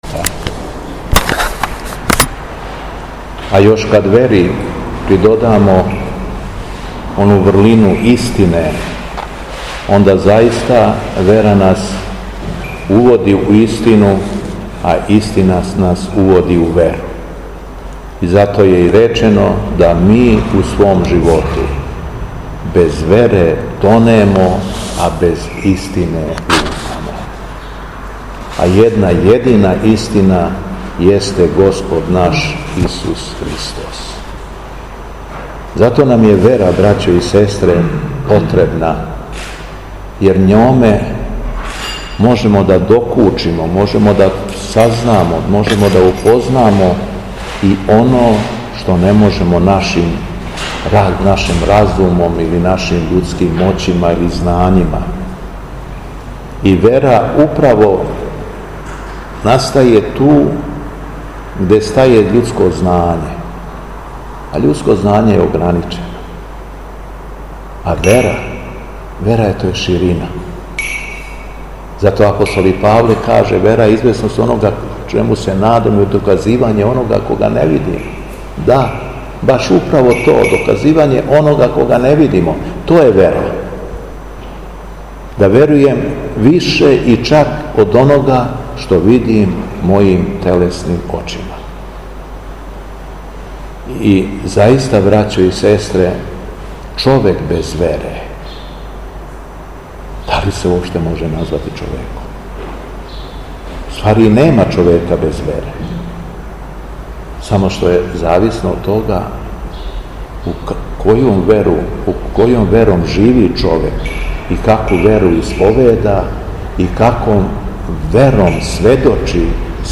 Беседа Његовог Високопреосвештенства Митрополита шумадијског г. Јована
После прочитаног јеванђелског зачала Високопреосвећени се обратио верном народу надахнутом беседом: